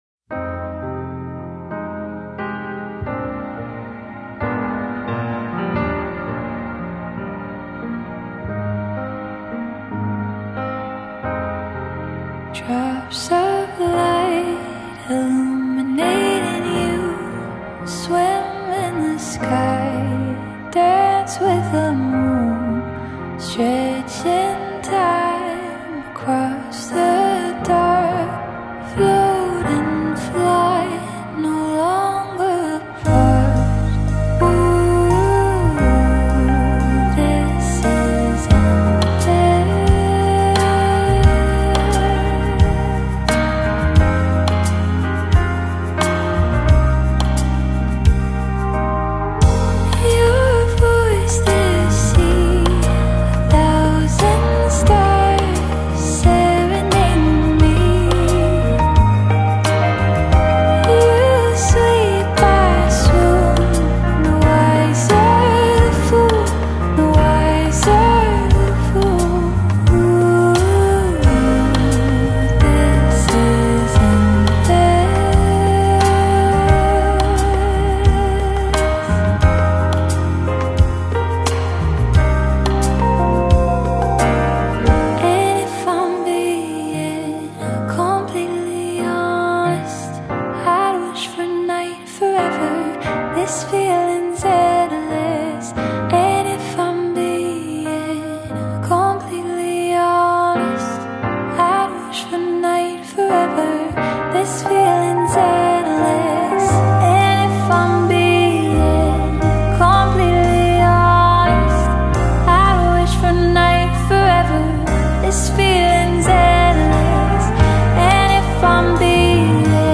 Indie
fretless electric bass